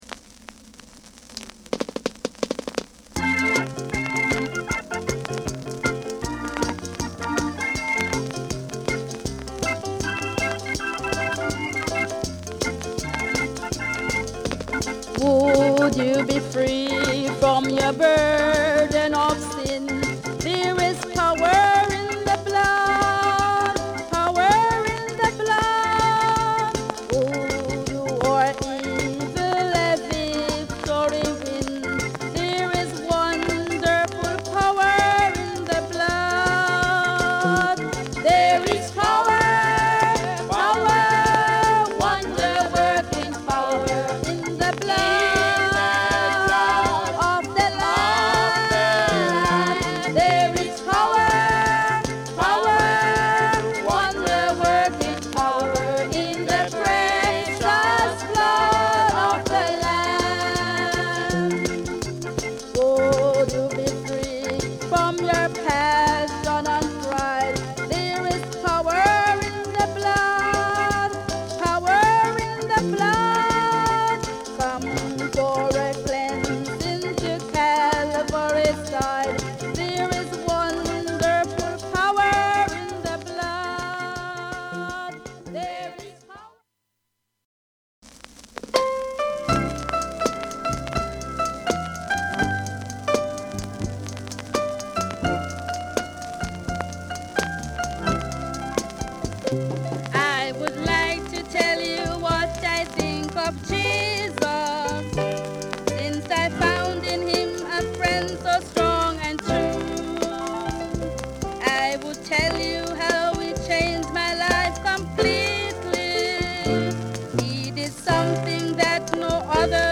Genre: Reggae Gospel
伝統讃美歌をジャマイカのリズムに乗せた素朴な仕上がりの中に、日常の祈りの温もりがある。